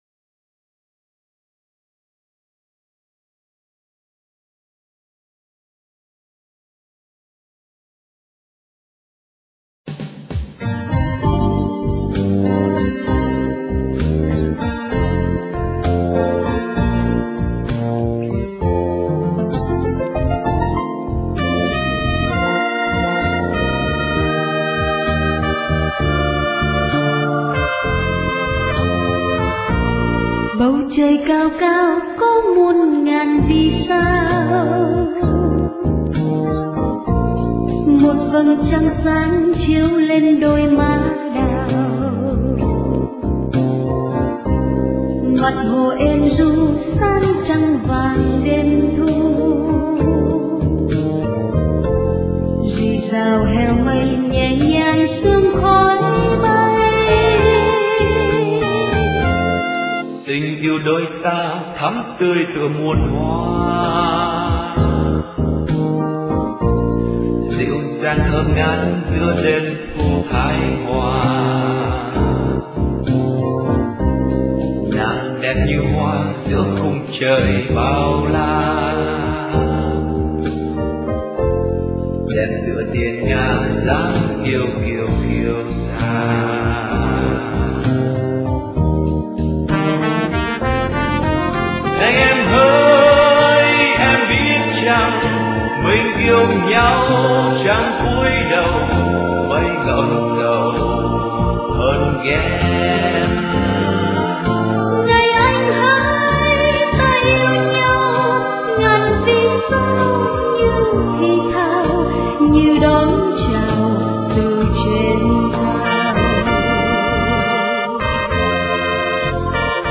Dòng nhạc : Nhạc Việt